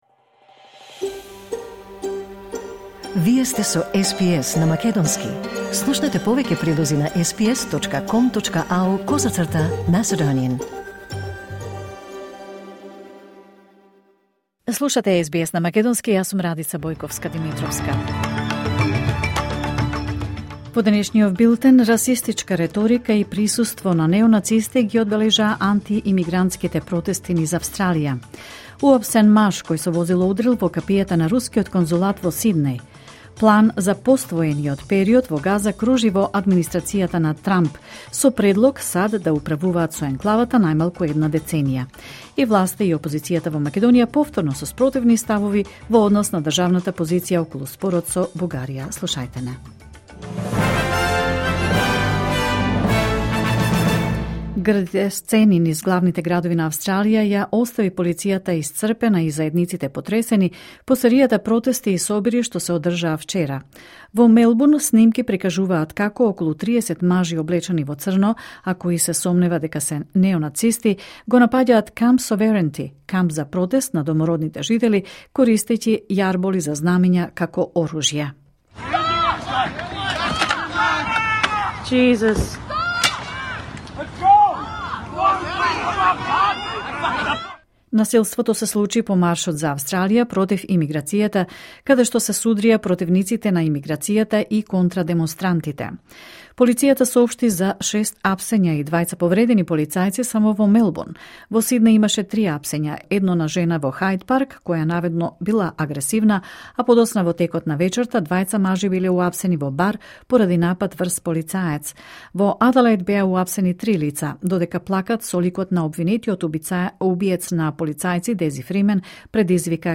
Вести на СБС на македонски 1 септември 2025